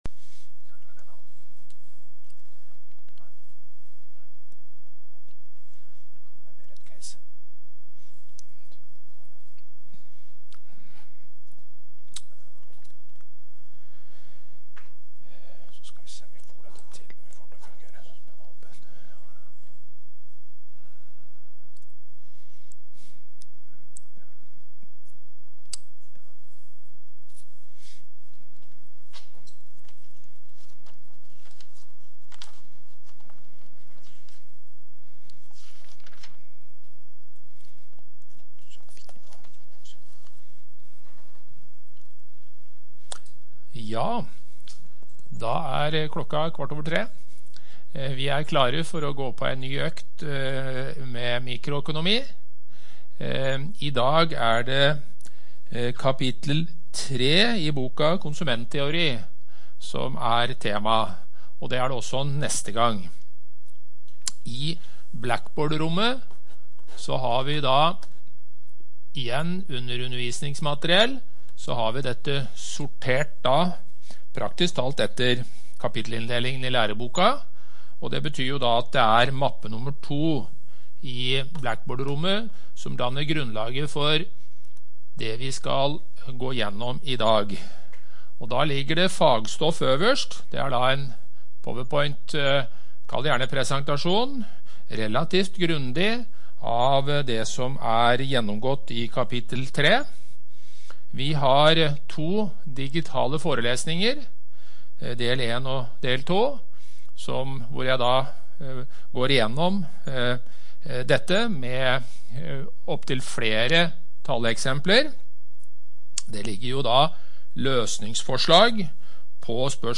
Forelesning mikroøkonomi 25.1.2021